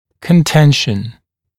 [kən’tenʃn][кэн’тэншн]разногласие, расхождение во мнениях; спор; раздор